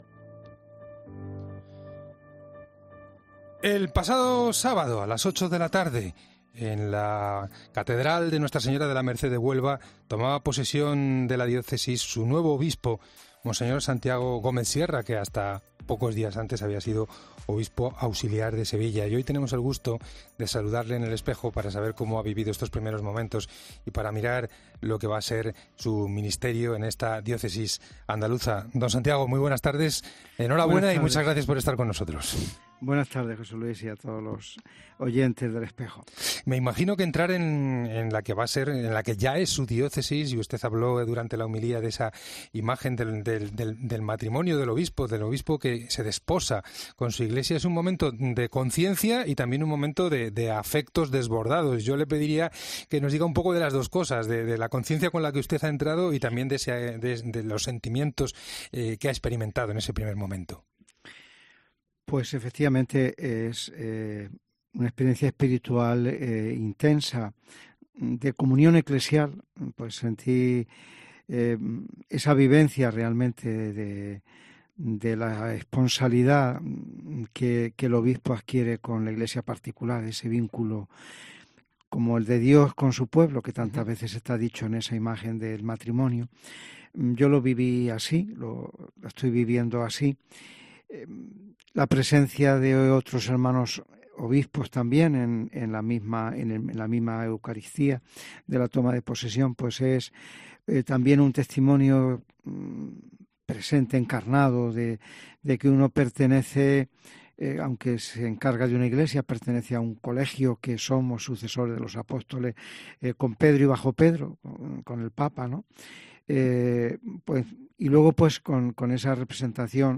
Monseñor Santiago Gómez, ha compartido con los oyentes de El Espejo las primeras impresiones de este mandato, que vive con gran ilusión.